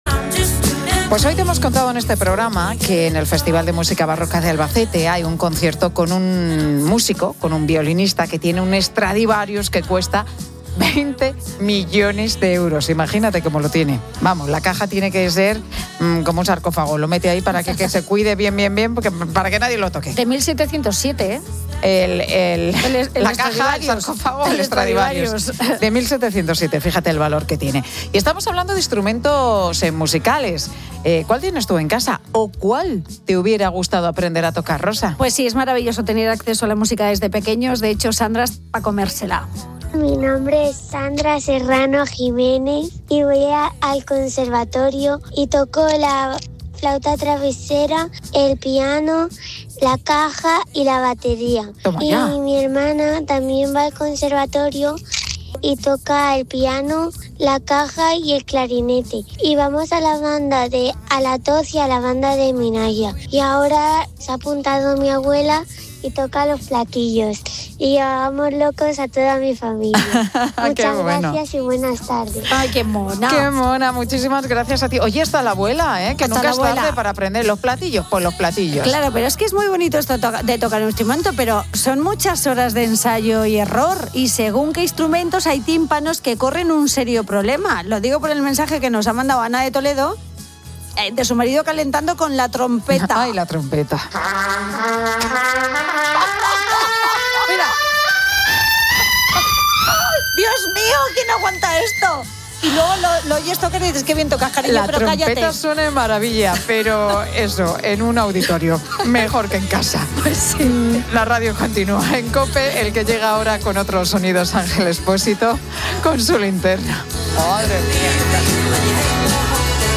Esta anécdota ha servido de pretexto para que los oyentes compartan sus propias experiencias musicales, inundando el programa de relatos personales.